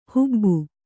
♪ hubbu